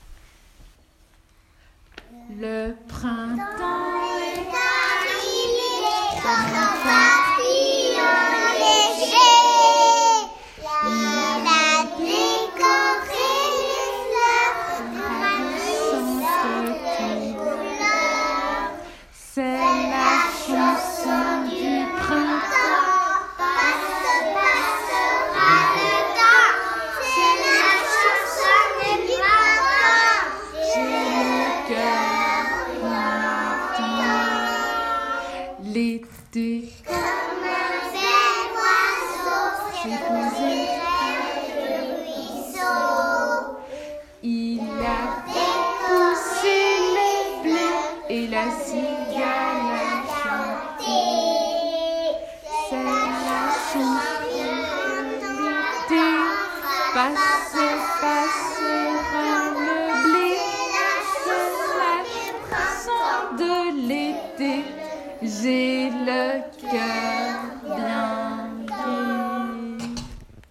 Fête de la crèche
Chanson du groupe de la Maternelle "Le printemps est arrivé"